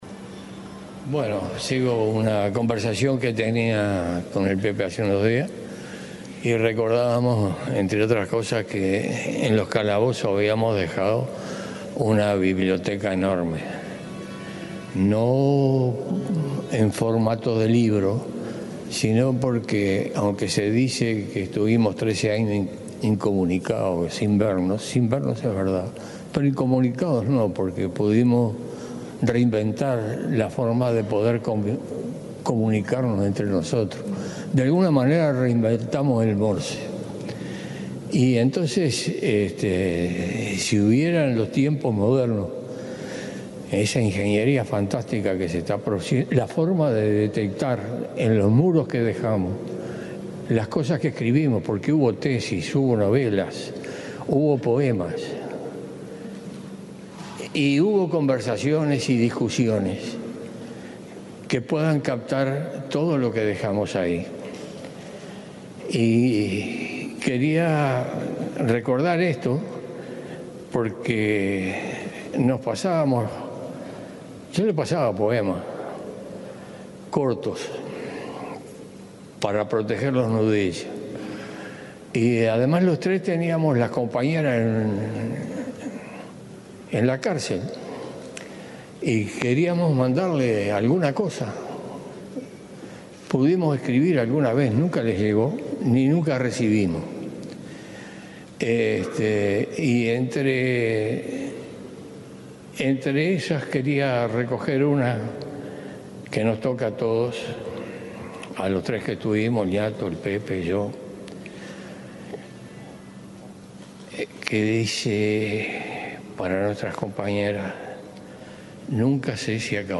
En el marco de las honras fúnebres al expresidente de la República José Mujica, Rosencof le dedicó palabras de homenaje.